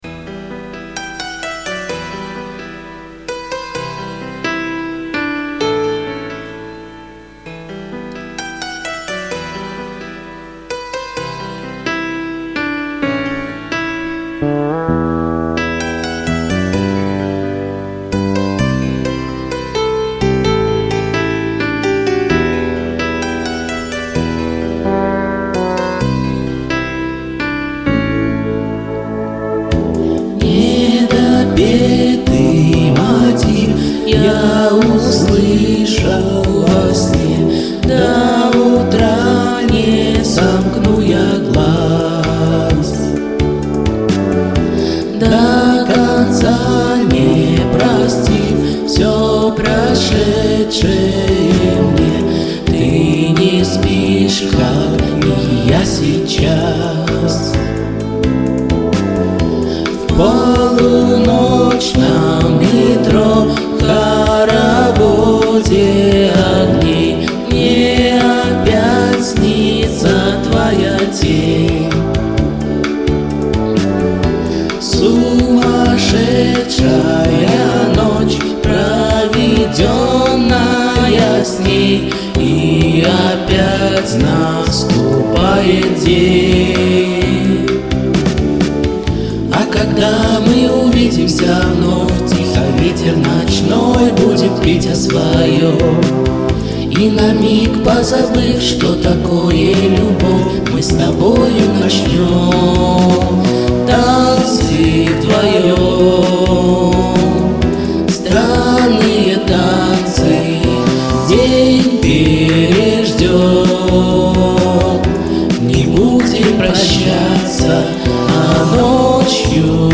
Качество не очень, прошу извинить.
Путем простого наложения!